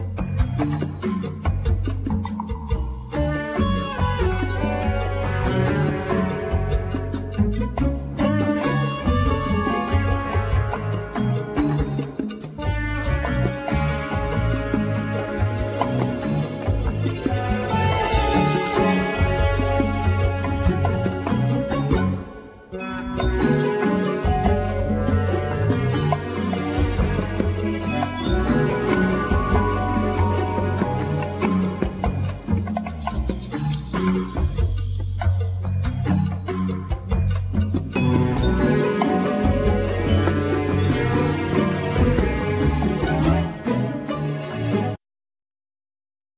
Guitar,Guitar Synth,Sample
Shakuhachi
Violin
Piano
Dumbek
Bass
Gaida,Kaval
Flute,Soprano+Alto+Tenor+Baritone Saxophone
Flugelhorn,Trumpet
Trombone
Tuba
Gakokoe(Bell)